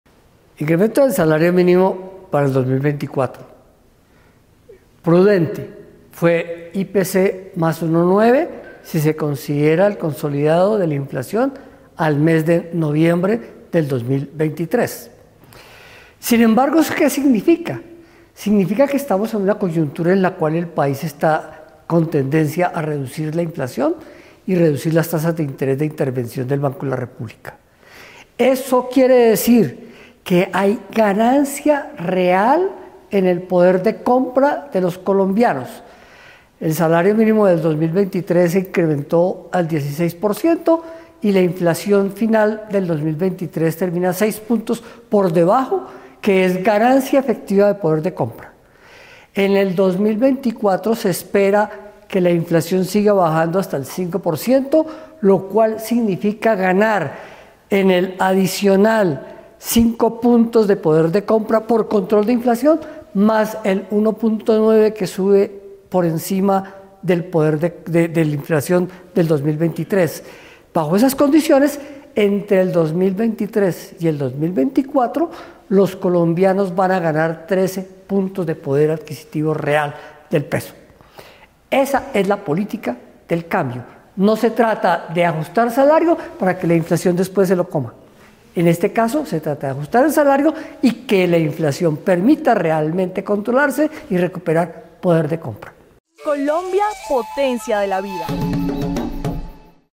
Declaración del Ministro de Hacienda sobre Incremento del Salario Mínimo para 2024